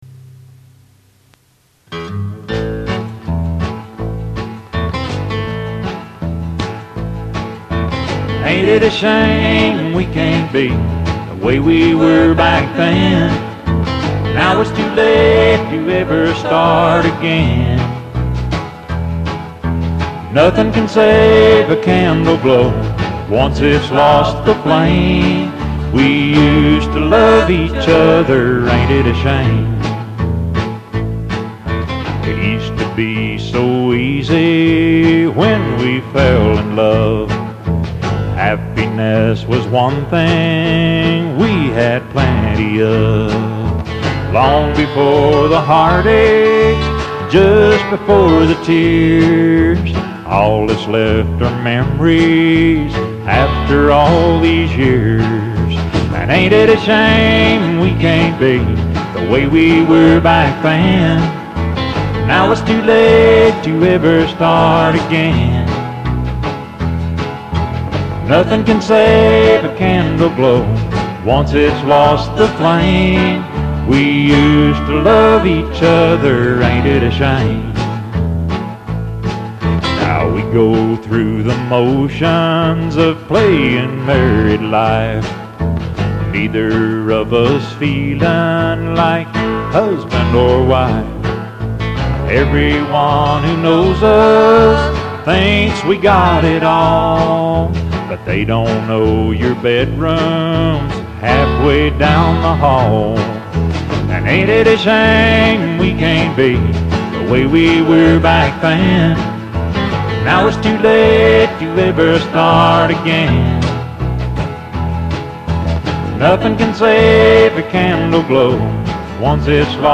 4 TRACK DEMO